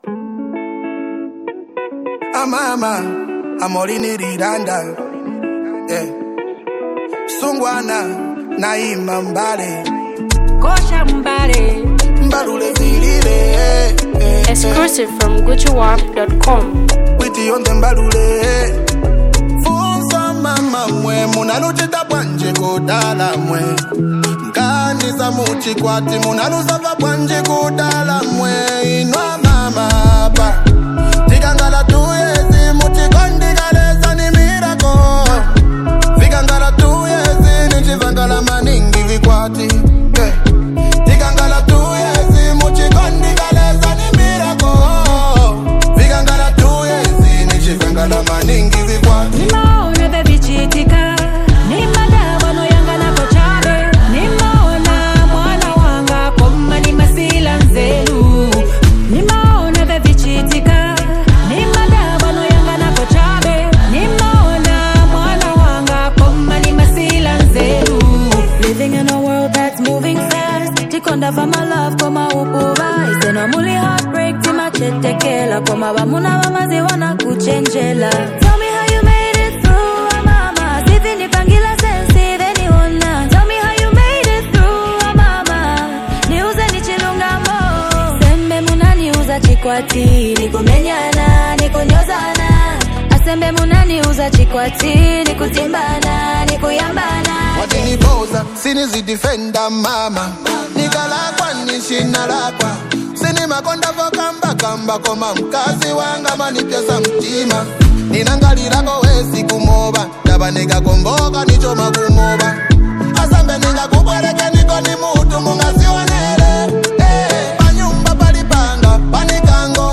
powerhouse vocals
legendary voice blends seamlessly
soulful tones
showcases exceptional vocal chemistry and musical finesse